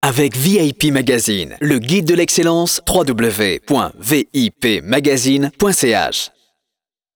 écouter en live les annonces radio ci-jointes, elle démarre après l'introduction suivante ''l'horoscope vous est offert par...''